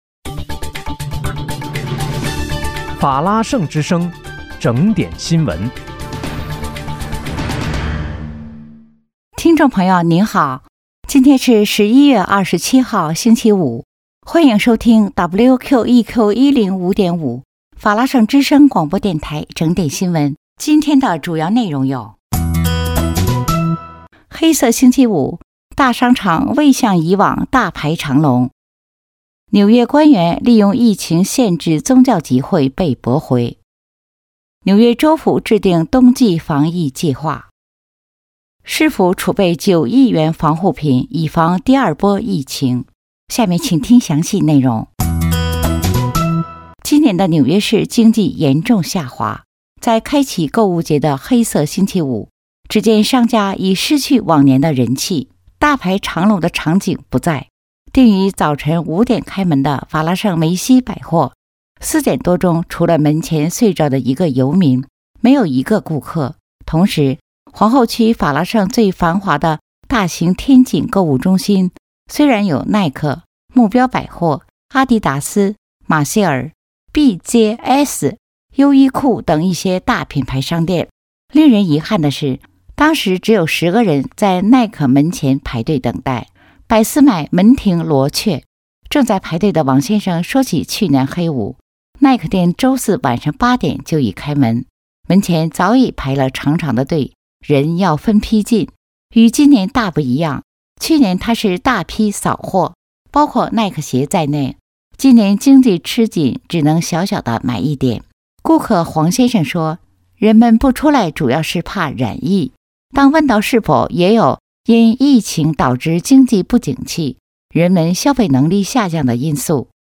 11月27日（星期五）纽约整点新闻